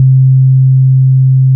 27SYN.BASS.wav